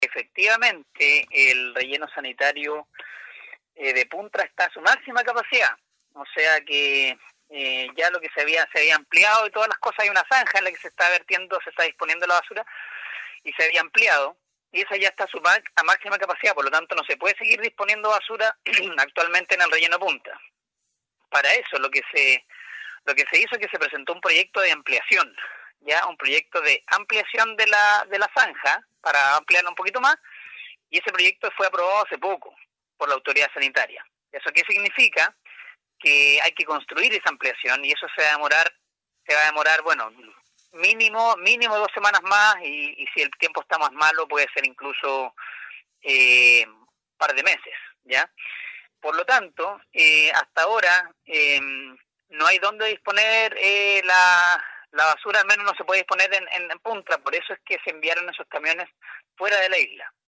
En torno a esta compleja temática, el concejal Samuel Mandiola, lamentó al punto que ha llegado este problema, el que ya se arrastra desde hace un par de años en la ciudad de Ancud.